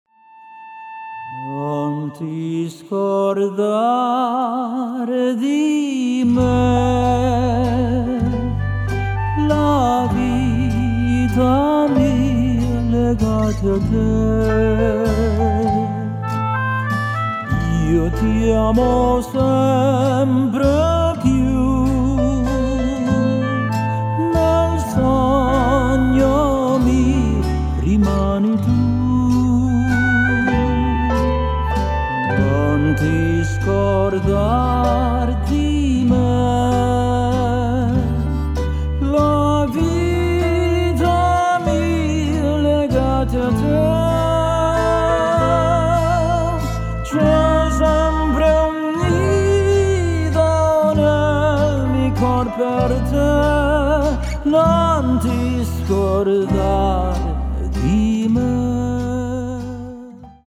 Solo
En rørende tenor performance, smukke italienske sange, hits, kendte arier.